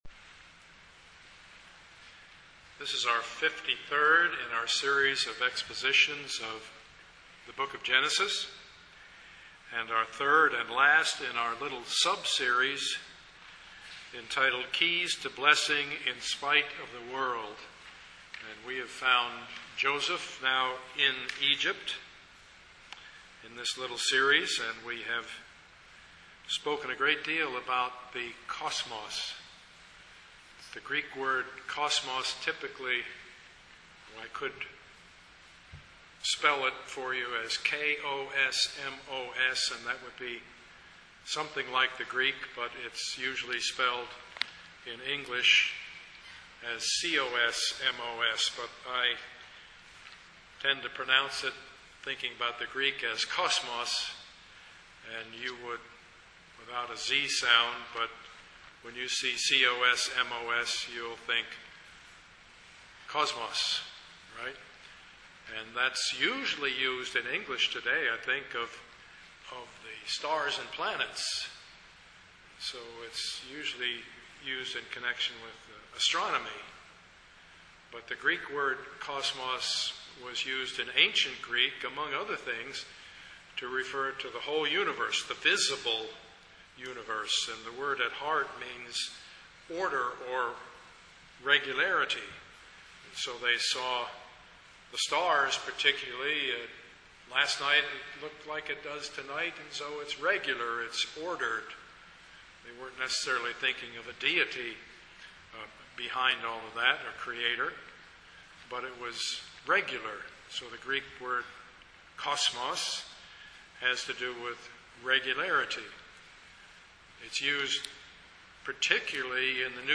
Passage: Genesis 39:1-41:57 Service Type: Sunday morning
Sermon